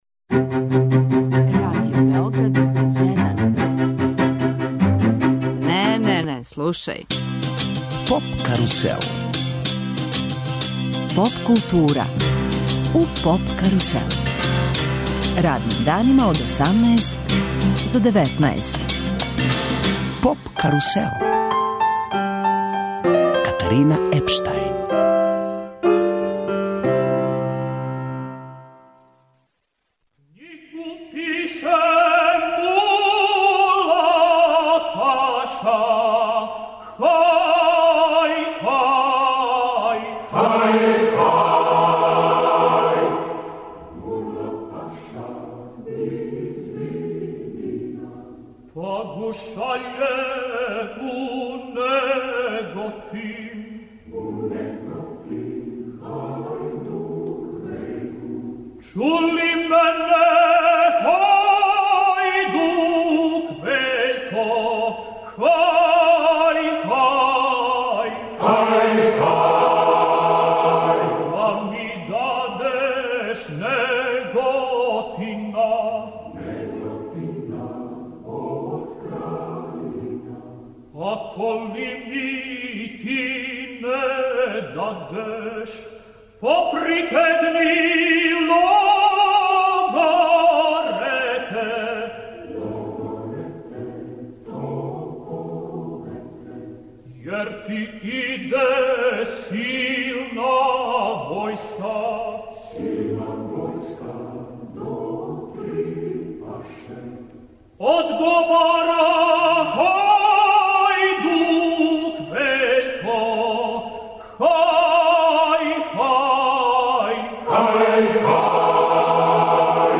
Емисија се реализује из Неготина где сутра почињу 'Мокрањчеви дани'.